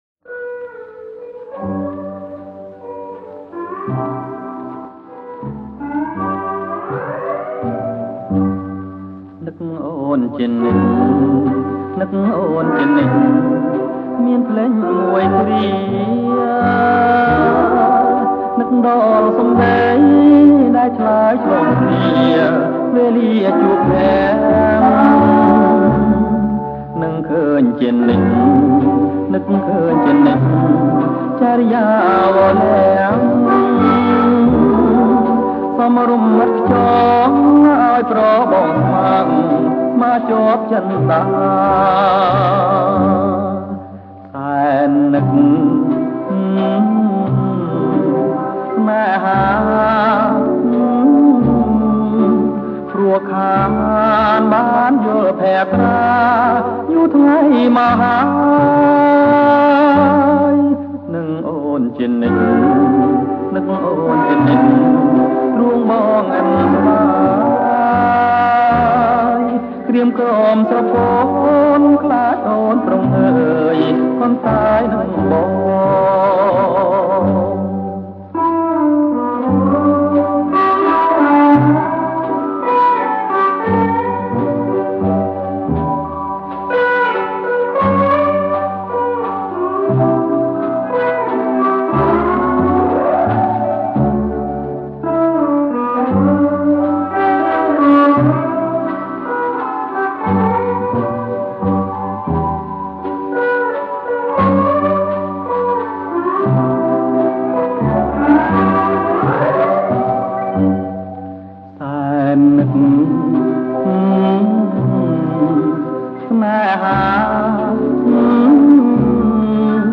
• ចង្វាក់ Boston